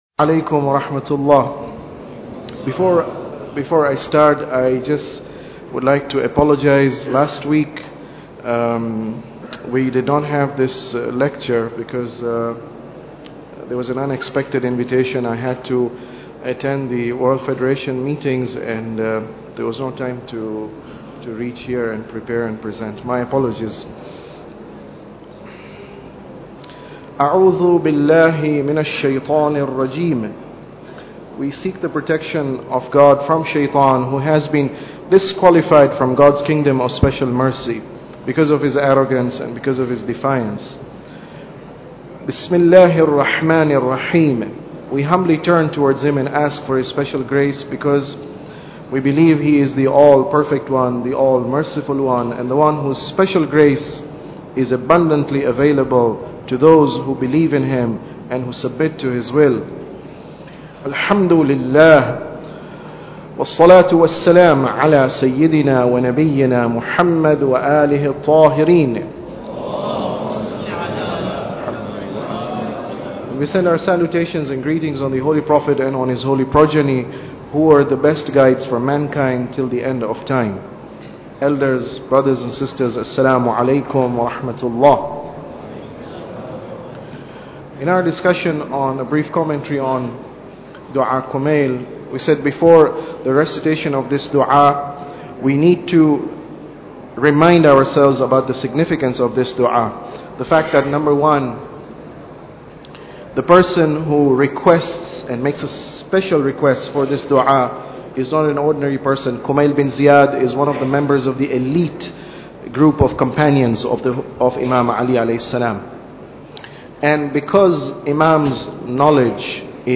Tafsir Dua Kumail Lecture 11